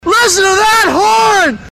Play Hornnnnnnnnn!
Play, download and share Hornnnnnnnnn! original sound button!!!!